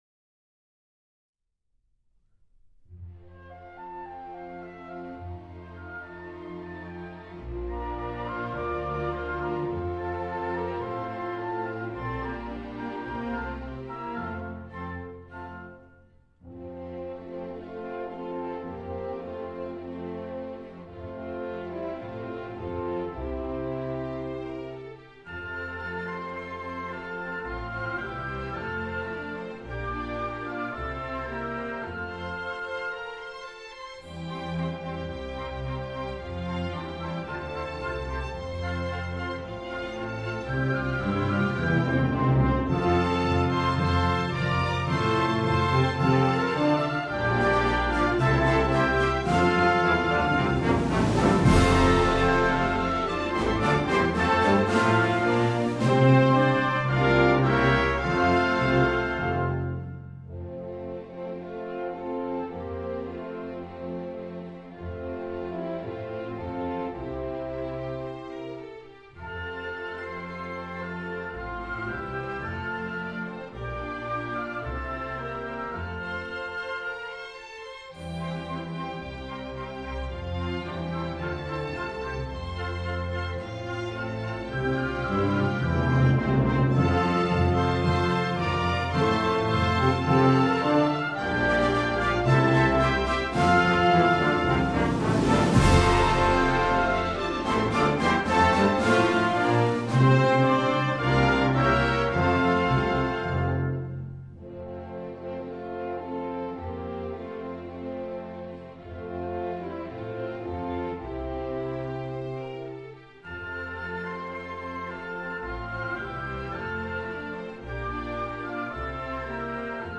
オーディオ校歌　～オーケストラ～
校歌　～オーケストラver.～[MP3：3.48MB]